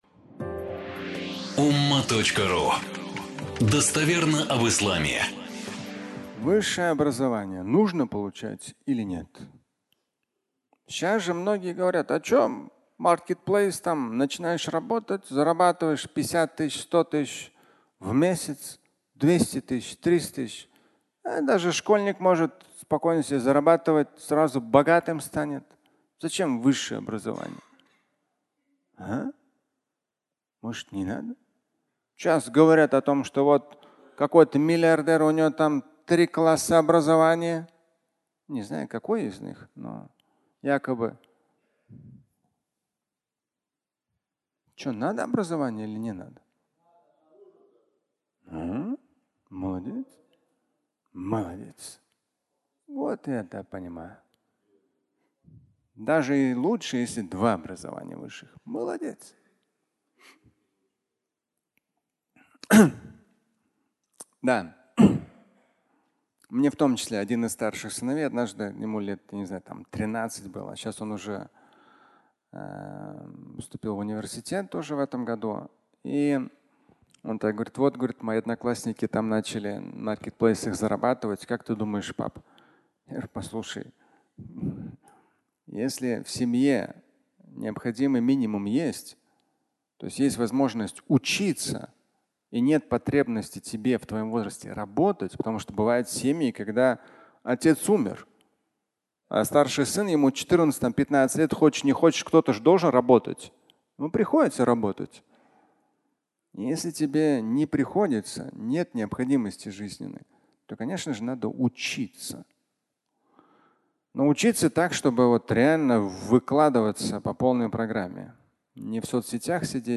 Высшее образование (аудиолекция)
Фрагмент пятничной лекции